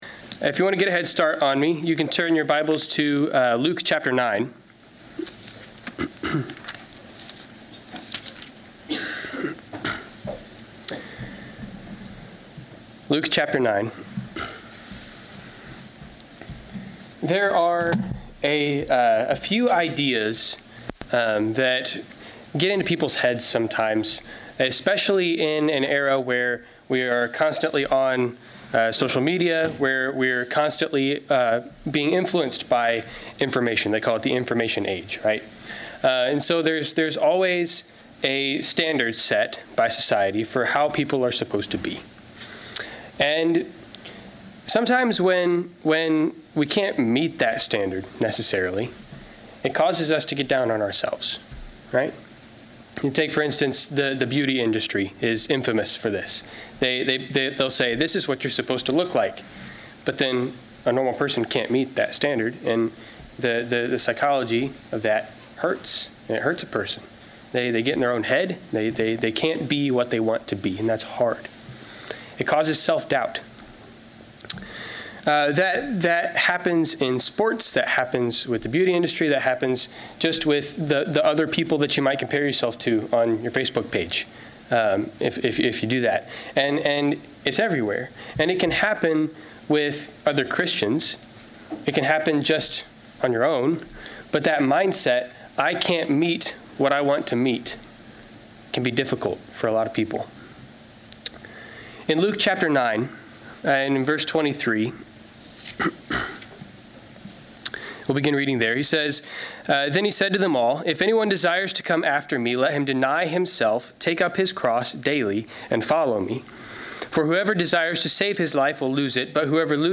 Service Type: Sunday 11:00 AM